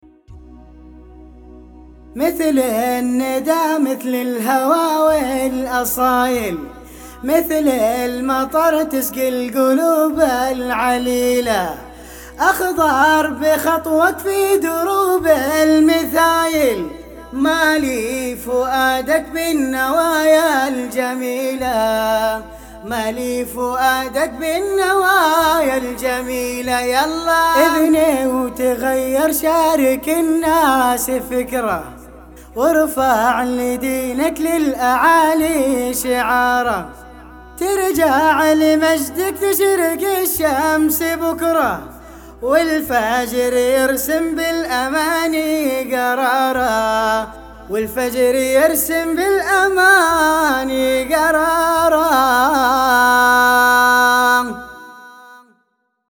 حجاز